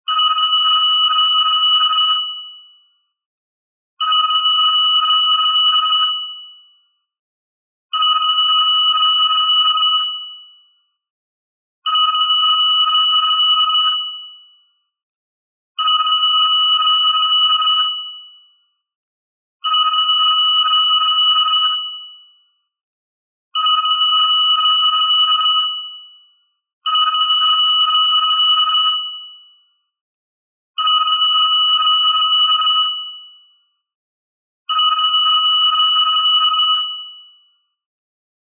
あなたのスマートフォンをアンティーク電話のように響かせよう！。